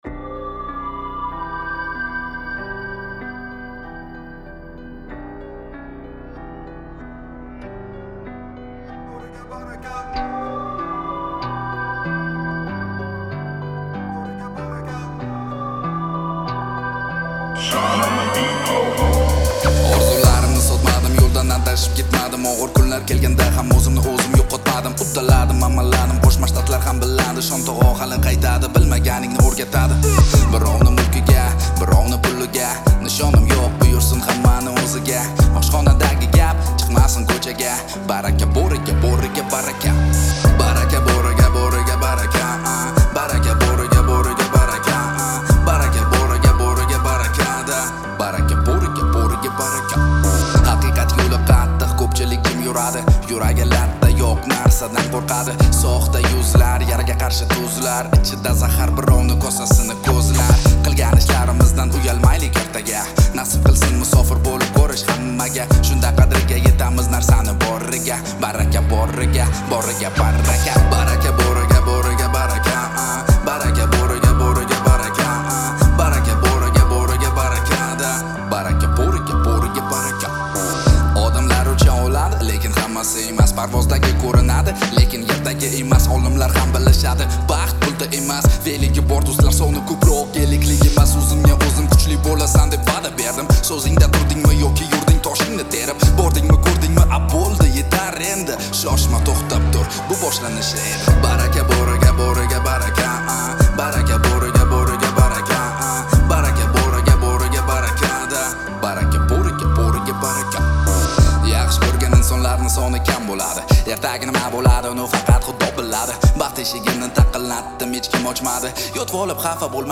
Трек размещён в разделе Узбекская музыка / Рэп и хип-хоп.